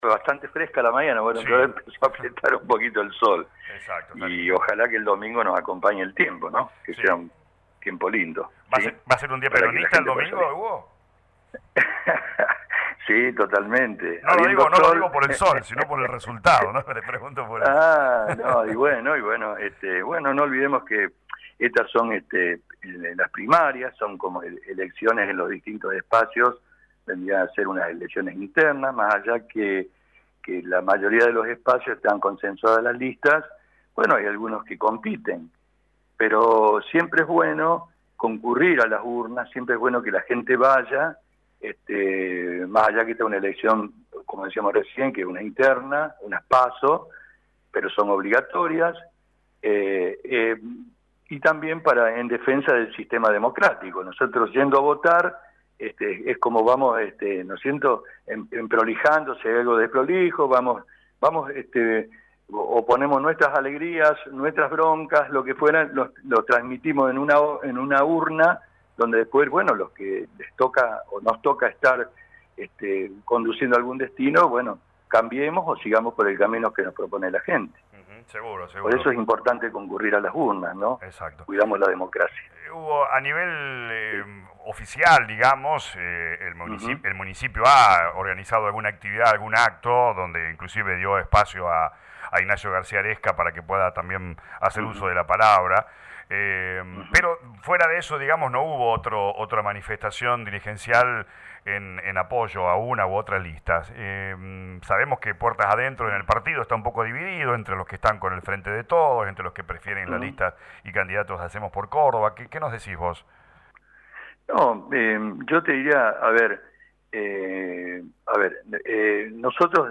habló en las últimas horas de campaña de cara a las elecciones con LA RADIO 102.9.